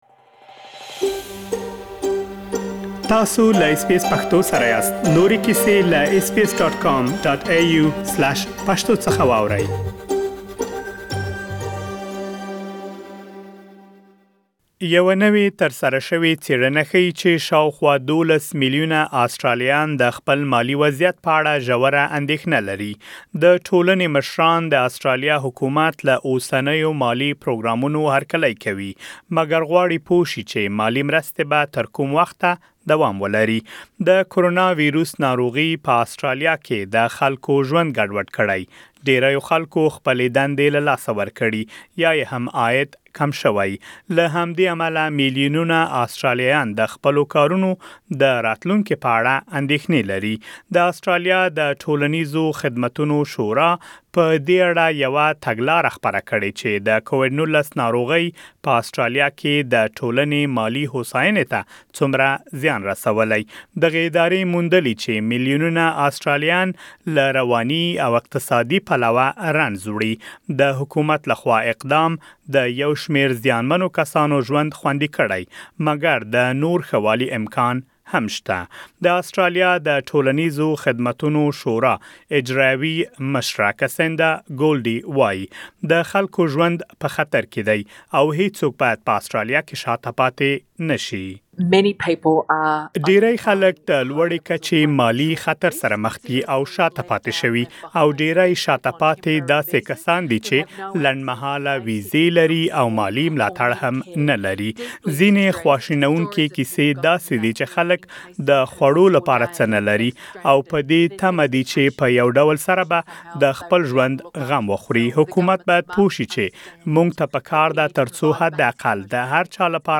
تاسو کولای شئ، پدې اړه لا ډېر معلومات زمونږ په غږیز رپوټ کې واورئ.